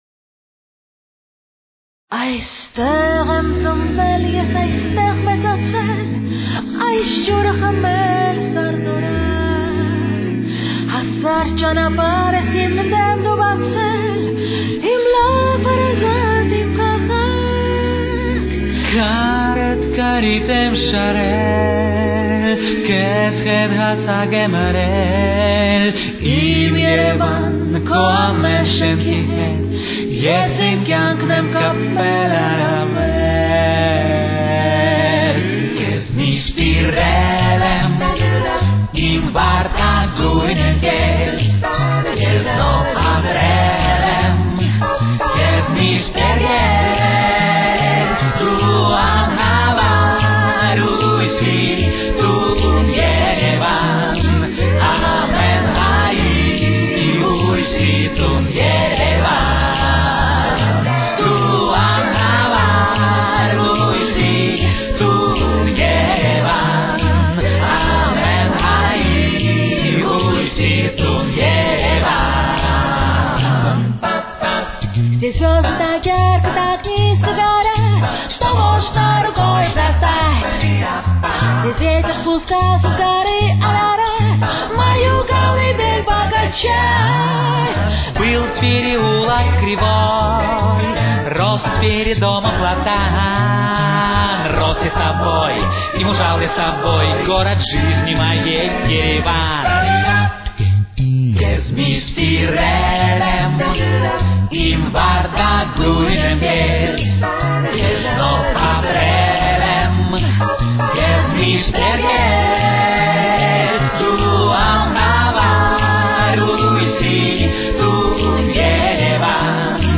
с очень низким качеством (16 – 32 кБит/с)
Тональность: Ми мажор. Темп: 76.